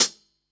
HIHAT772.WAV